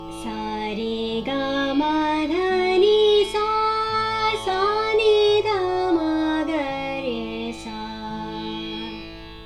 The solfege syllables Sa-Ri-Ga-Ma-Pa-Da-Ni-Sa are used for all scales.
Raag Marwa (hexatonic)
One of the interesting things about Raag Marwa is that it de-emphasizes the root note "sa." Probably because of this, it is a somewhat unsettling raga, and mainly evokes dark moods of foreboding and anxiety.